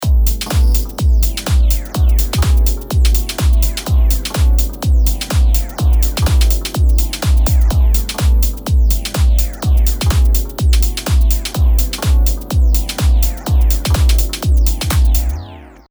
では次にディレイタイムの変化とブロック3の動きを連動させてみます。
この設定では、ドットが上にいくにつれ、ディレイのファインチューンがあがるので、音の揺れが激しくなっていきます。そしてその動きが1小節ごとなので、ちょっと酔っちゃいそうですね。